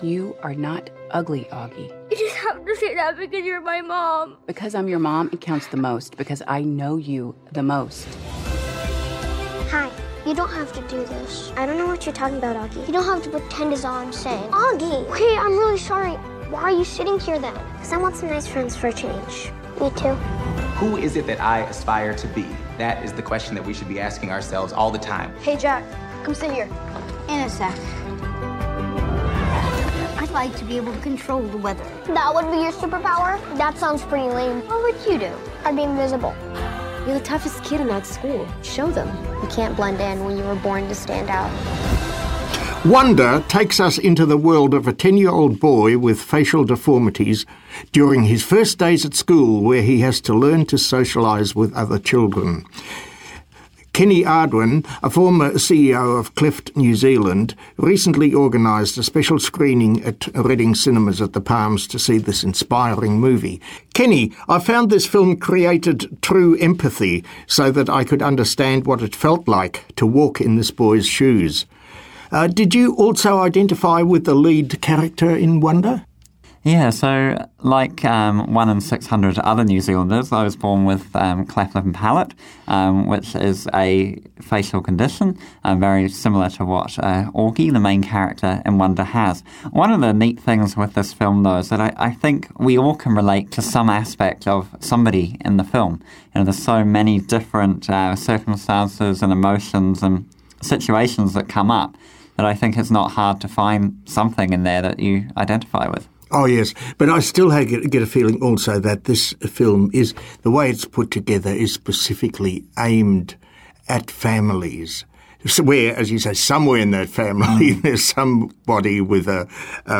movietalk-interview.mp3